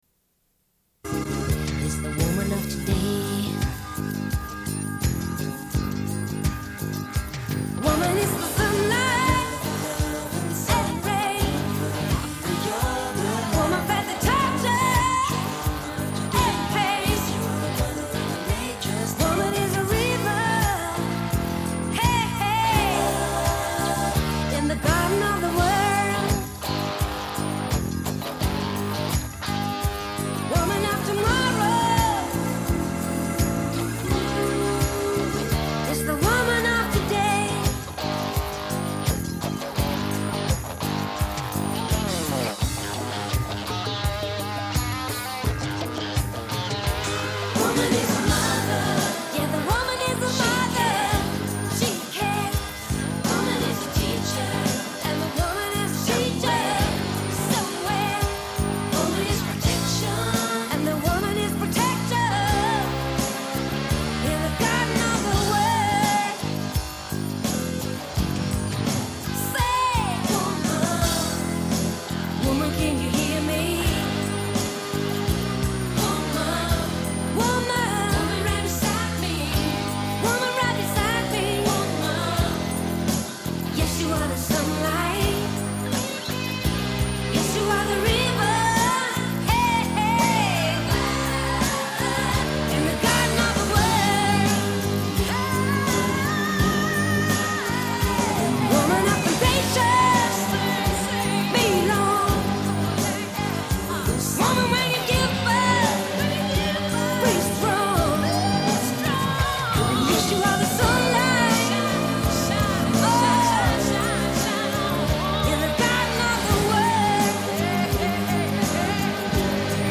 Une cassette audio, face B28:37
Radio